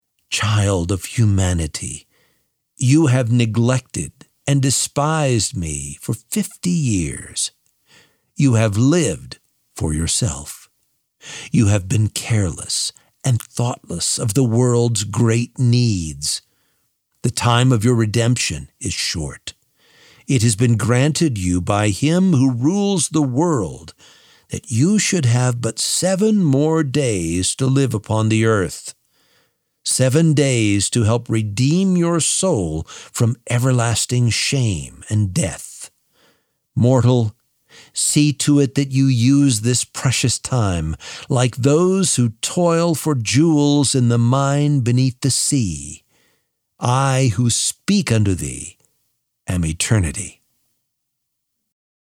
Audiobook: Seven Days Left - MP3 download - Lamplighter Ministries
7-Days-Left-Audiobook-Sample.mp3